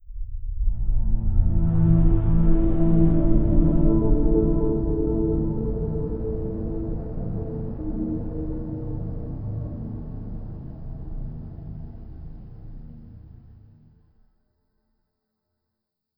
Samsung Galaxy S170 Startup.wav